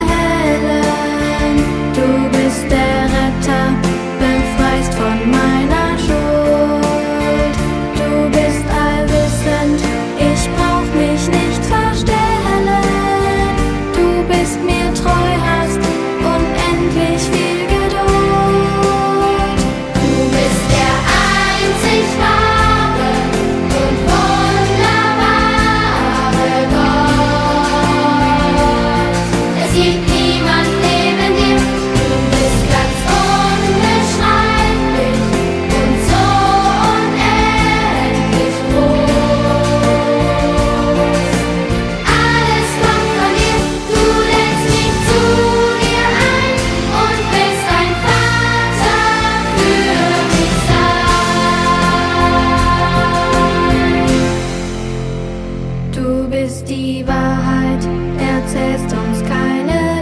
Ein Musical für Kids & Teens